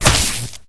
flesh3.ogg